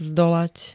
Zvukové nahrávky niektorých slov
xvvd-zdolat.ogg